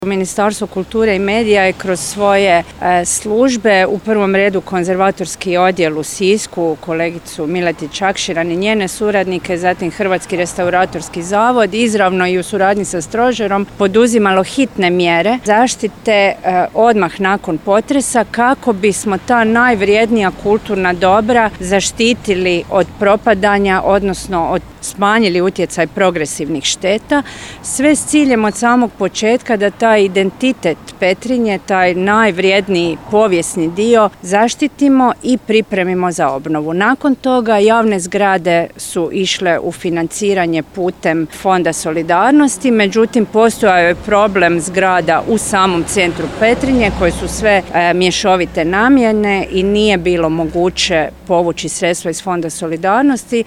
Ministrica Obuljen Koržinek naglašava kako je zadovoljna provedbom obnove